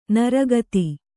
♪ nara gati